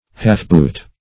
Search Result for " half-boot" : The Collaborative International Dictionary of English v.0.48: Half-boot \Half"-boot`\ (h[aum]f"b[=oo]t`), n. A boot with a short top covering only the ankle.